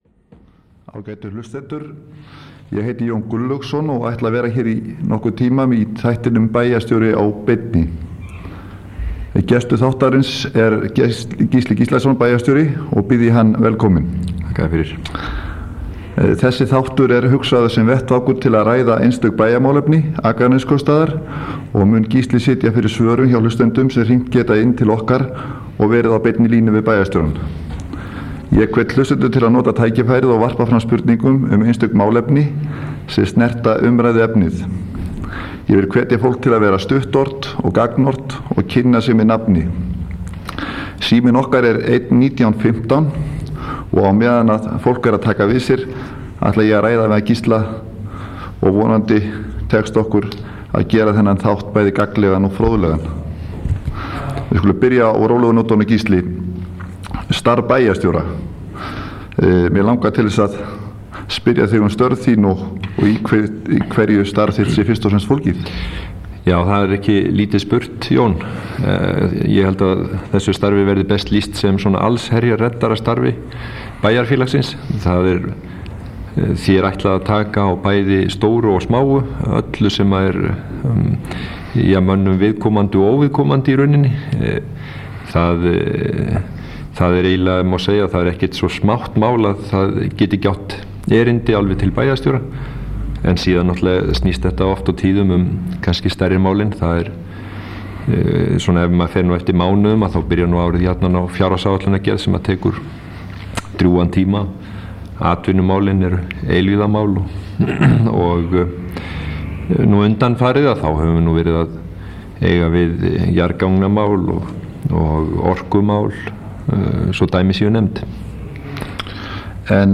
Gísli Gíslason bæjarstjóri situr fyrir svörum. Hlustendur geta hringt inn og lagt spurningar fyrir bæjarstjórann.